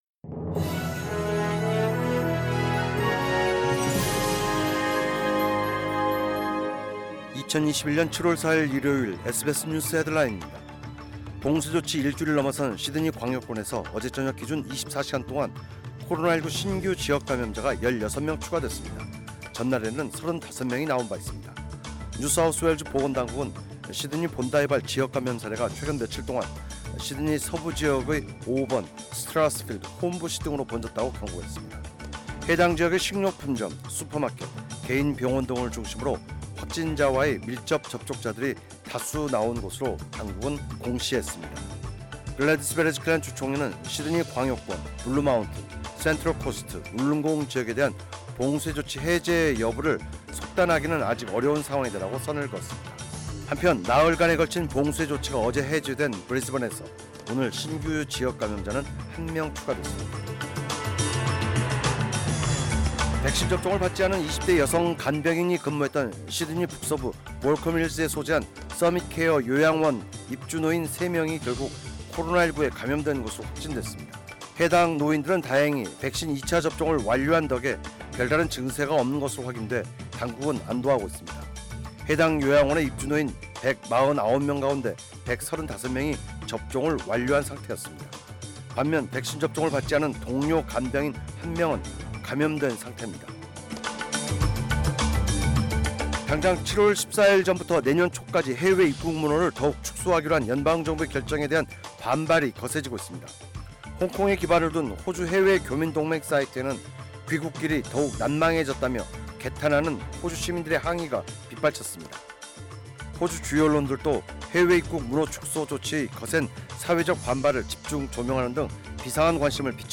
2021년 7월 4일 일요일 SBS 뉴스 헤드라인입니다.